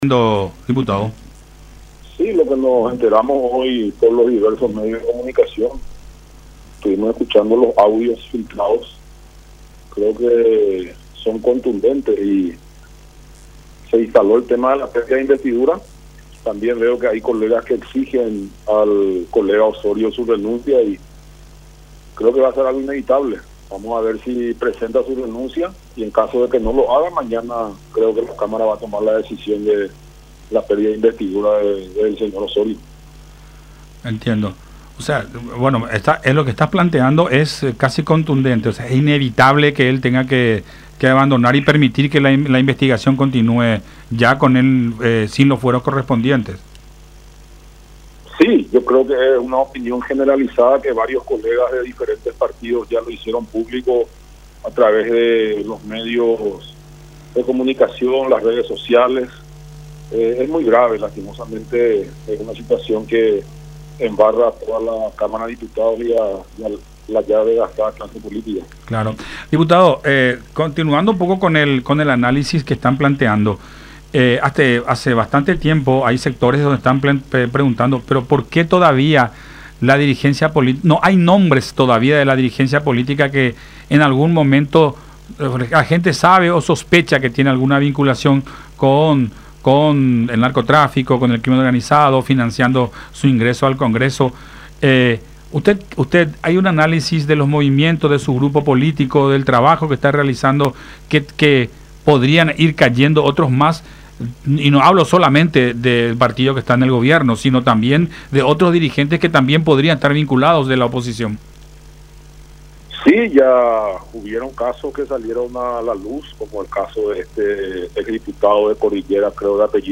“Estuvimos escuchando los audios filtrados y son contundentes y se ha instalado el tema de la pérdida de investidura (…) El narcotráfico está instalado en las diferentes áreas de la política y es un cáncer que se tiene que extirpar”, dijo Galaverna en conversación con Buenas Tardes La Unión.